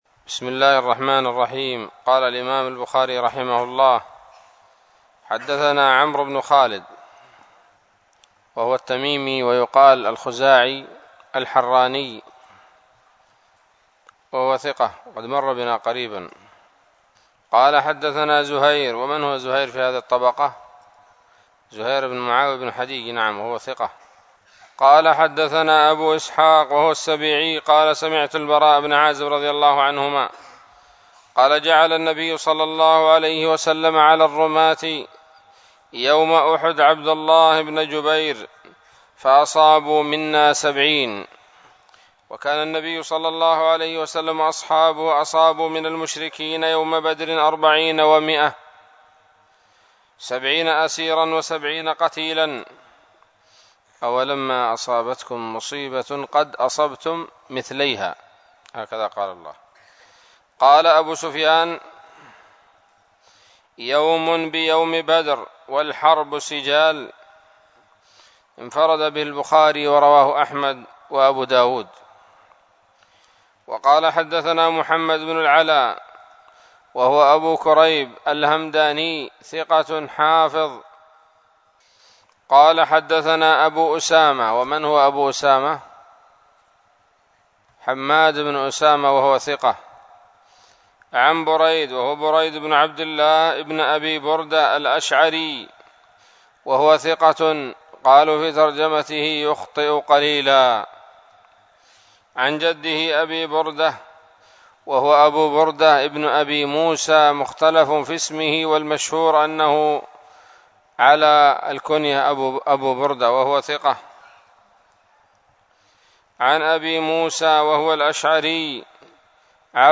الدرس الرابع عشر من كتاب المغازي من صحيح الإمام البخاري